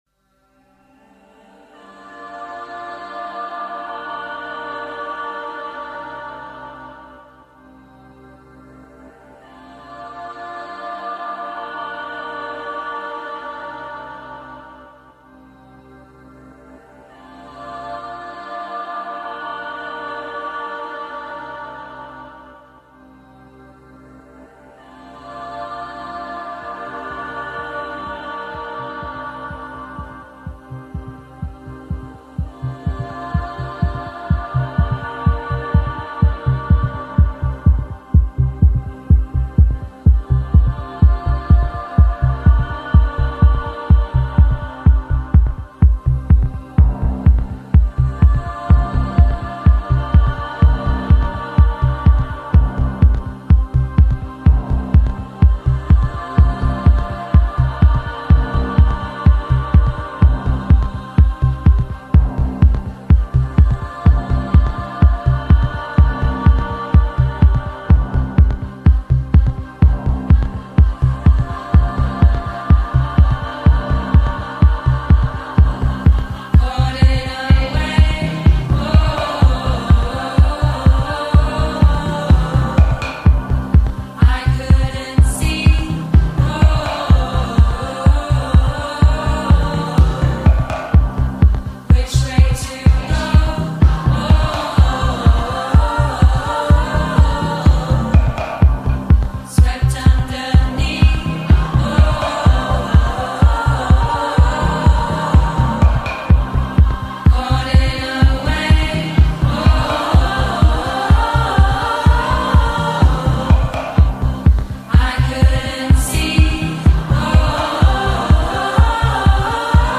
English electronic duo
London-based female collective
all-female choir